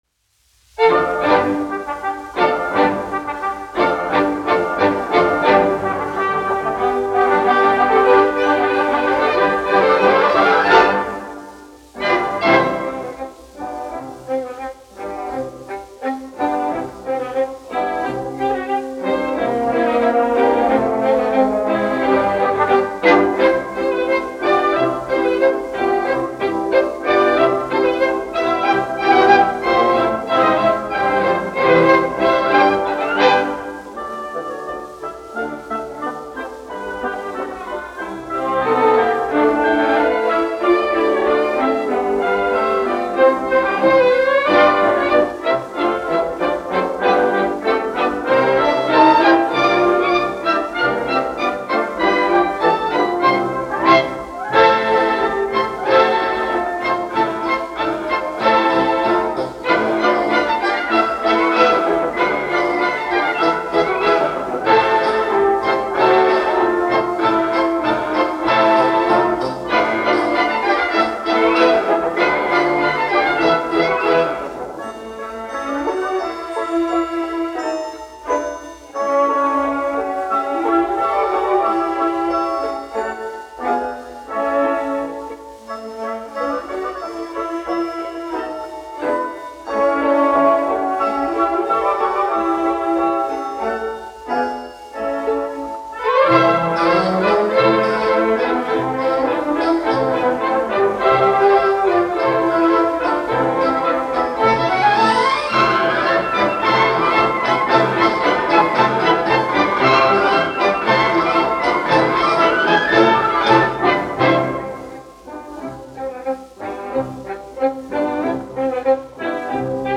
Ленинградская государственная филармония. Академический симфонический оркестр, izpildītājs
Jansons, Arvīds, 1914-1984, diriģents
1 skpl. : analogs, 78 apgr/min, mono ; 25 cm
Tautas deju mūzika -- Latvija
Latvijas vēsturiskie šellaka skaņuplašu ieraksti (Kolekcija)